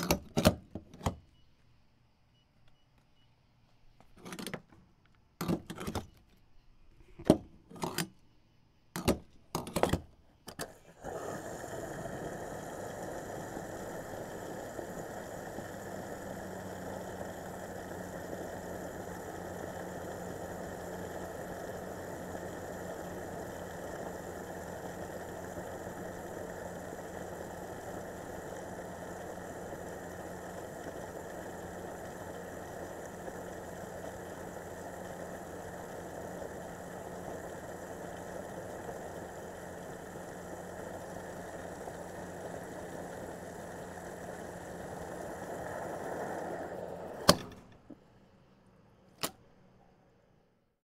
Gas Pump Fill Up